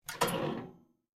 Звуки духовки
Звук распахнутой дверцы духовки